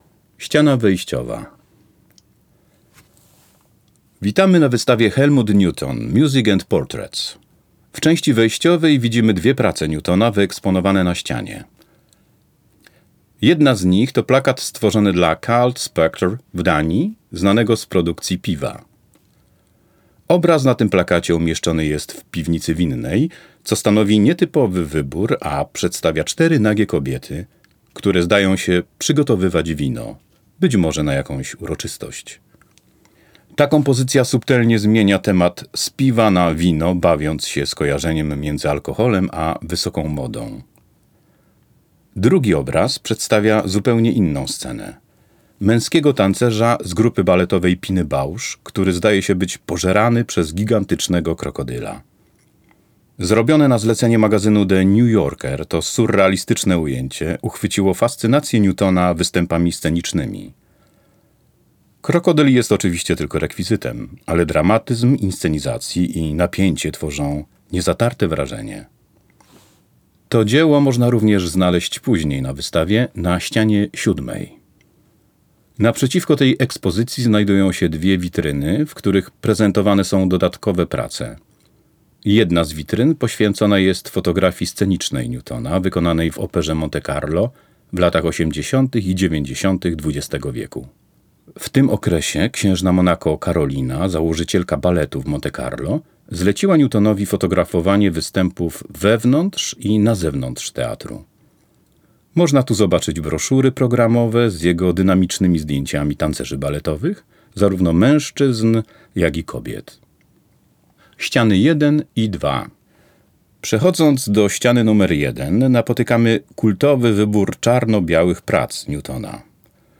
Pobierz plik audio z oprowadzaniem kuratorskim po wystawie Helmuta Newtona, Nie przegap żadnego koncertu.